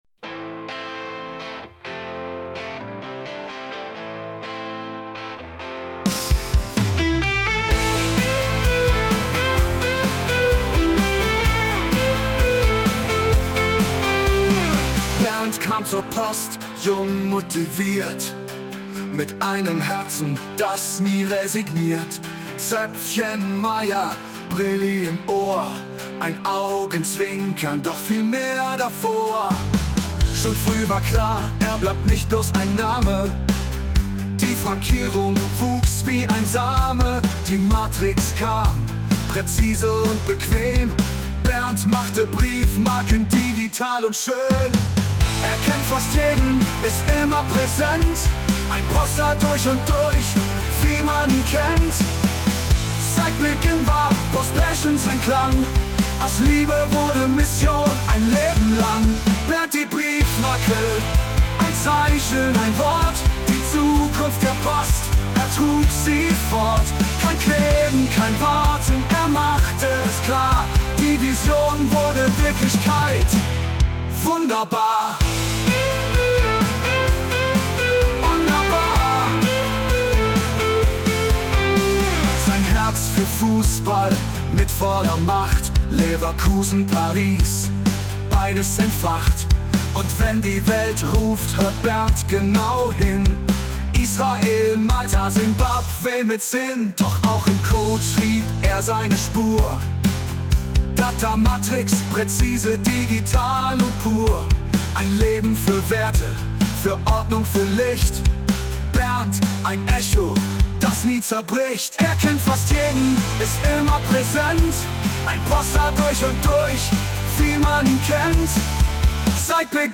von der KI getextet und komponiert